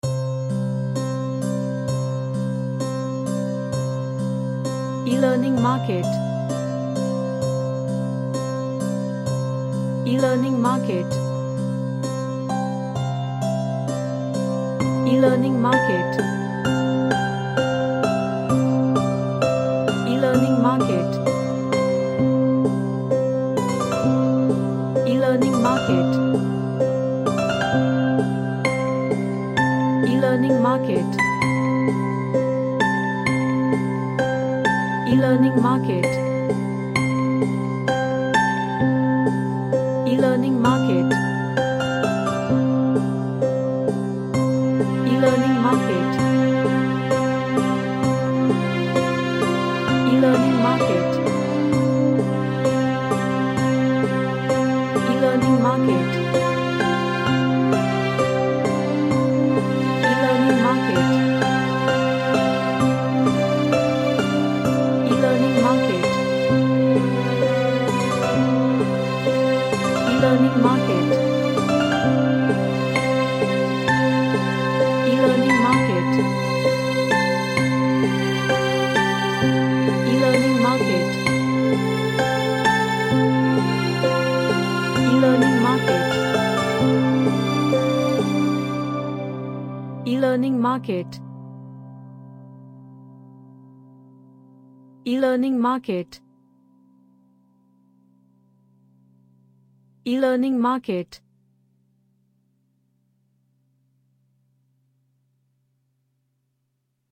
A emotional pianocimo track
Emotional